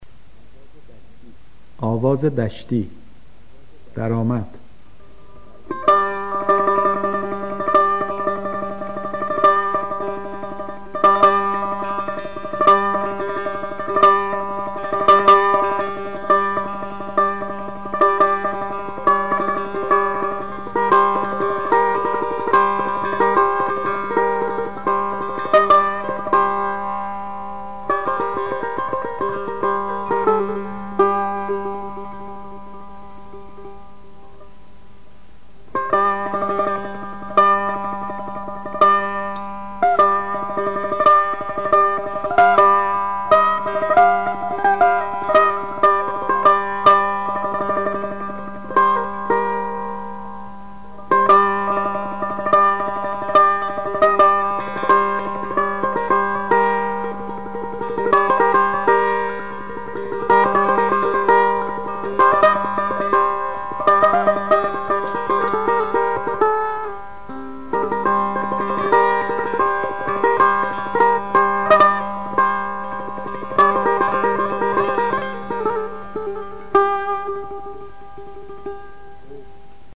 آواز دشتی ردیف میرزا عبدالله سه تار
درآمد، آواز دشتی